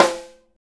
SNR 4-WAY 05.wav